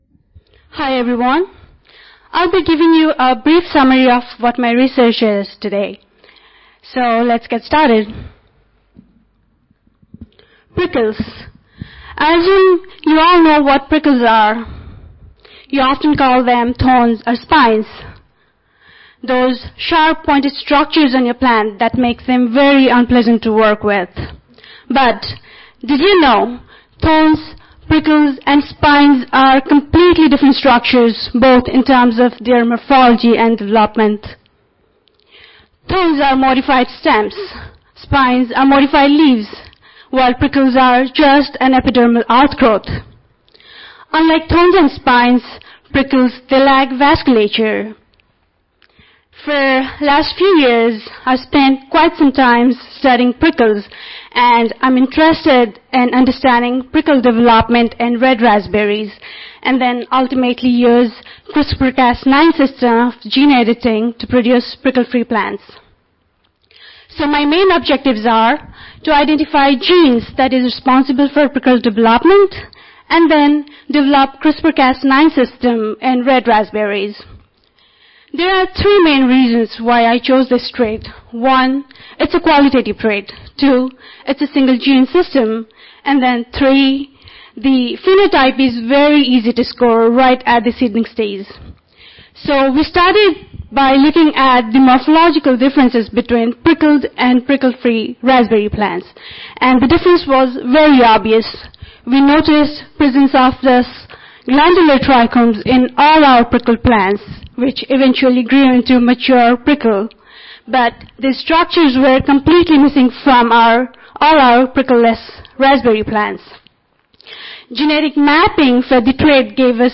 Scholars Ignite Competition
Georgetown West (Washington Hilton)
Audio File Recorded Presentation